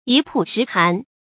注音：ㄧ ㄆㄨˋ ㄕㄧˊ ㄏㄢˊ
一暴十寒的讀法